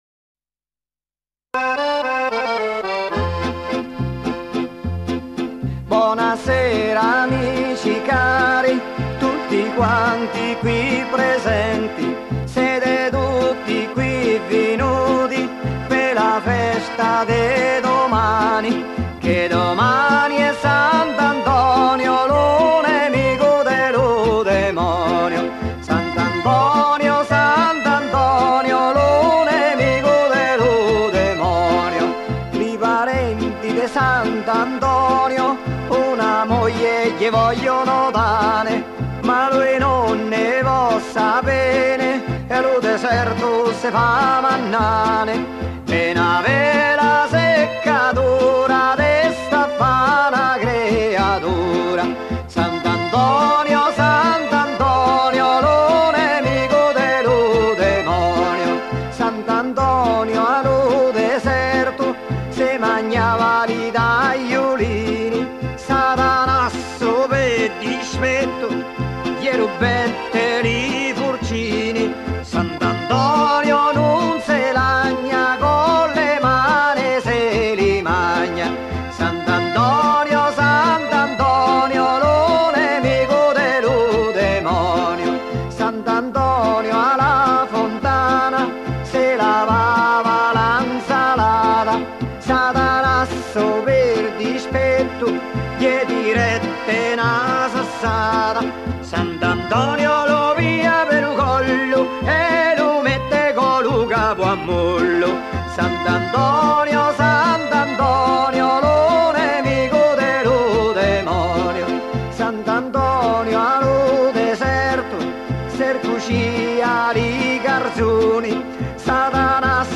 eccovi un canto tradizionale noto anche in Umbria relativo al giorno di S. Antonio, il 17 gennaio.
canto che viene da una vecchia registrazione
con il suo gruppo di cantori.